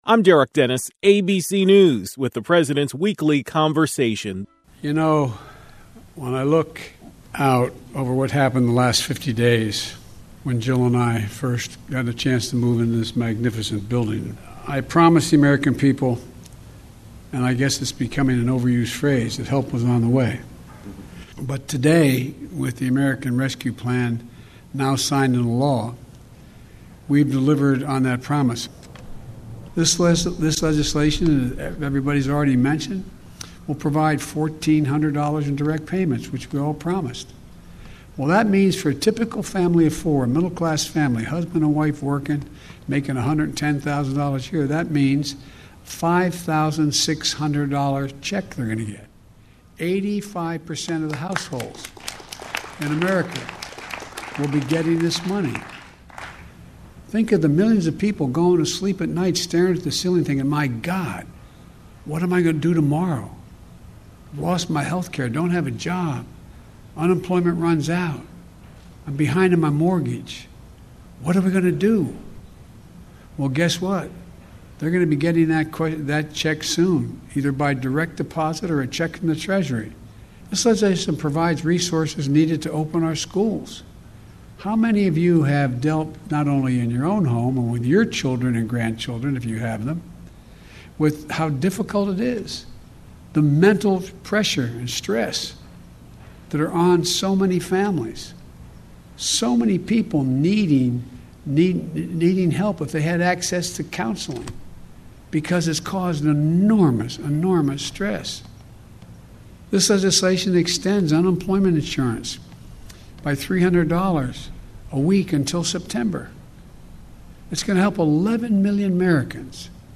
President Joe Biden thanked members of both the House and Senate at the White House Rose Garden.